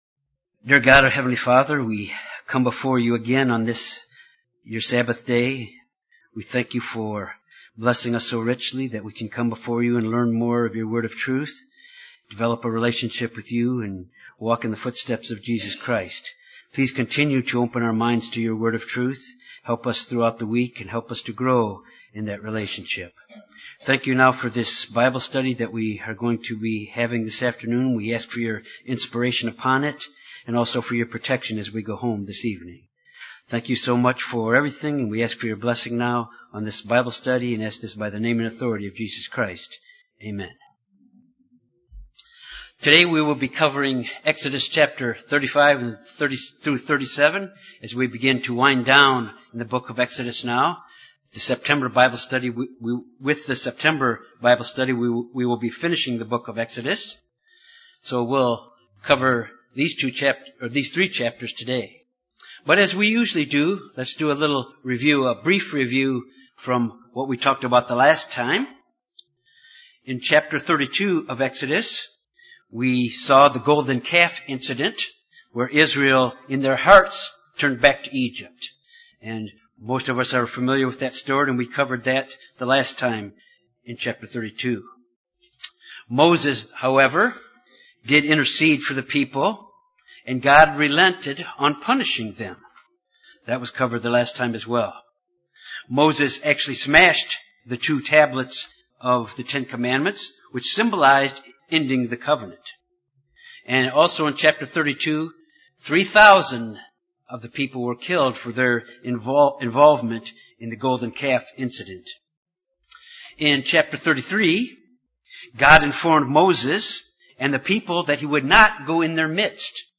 This Bible Study covers the actual construction of the tabernacle alone with its furnishings.